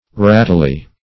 wrathily - definition of wrathily - synonyms, pronunciation, spelling from Free Dictionary Search Result for " wrathily" : The Collaborative International Dictionary of English v.0.48: Wrathily \Wrath"i*ly\, adv. In a wrathy manner; very angrily; wrathfully.